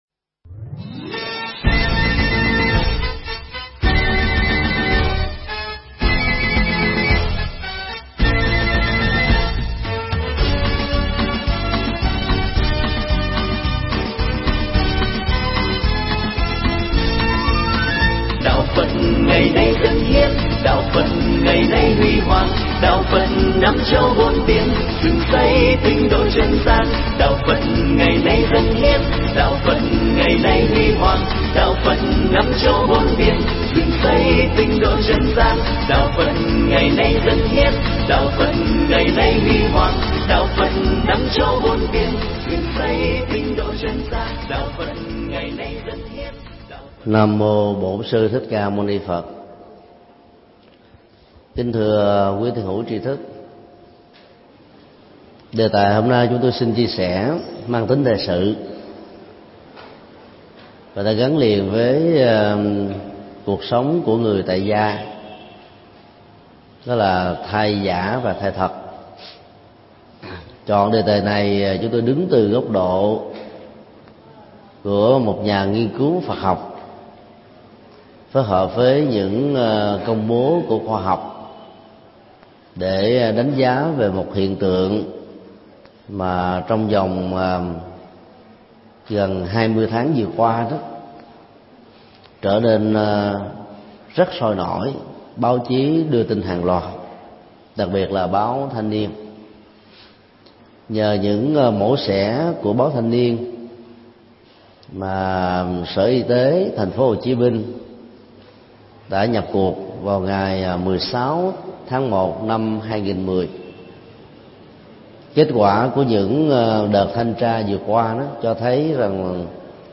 Tải mp3 Pháp thoại Thai thật và thai giả được thầy Thích Nhật Từ giảng tại chùa Xá Lợi ngày 31 tháng 01 năm 2010